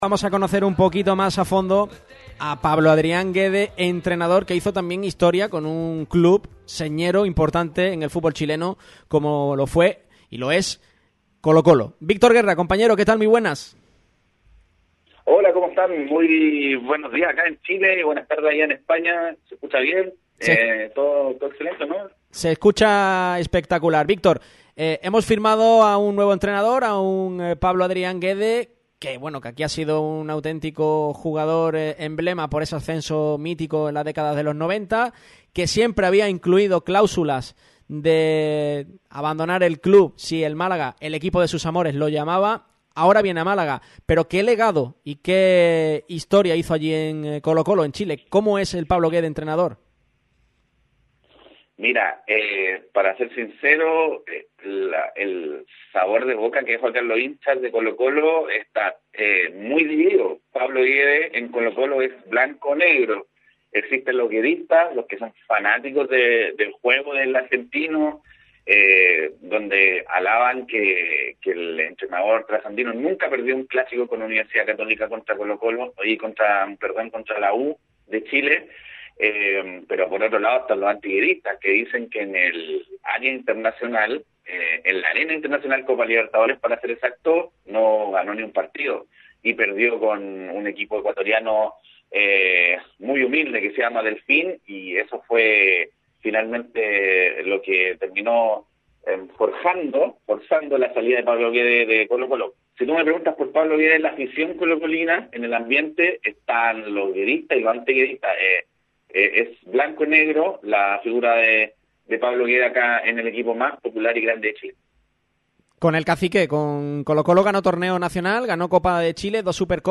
pasó por el micrófono de Radio MARCA Málaga para analizar la actuación de Pablo Guede en el conjunto 'zorro'.